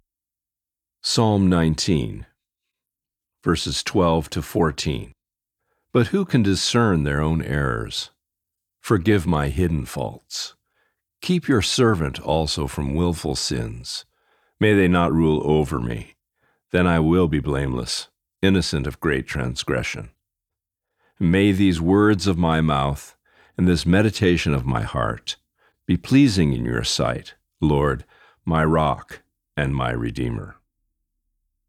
Today’s Reading: Psalm 19:12-14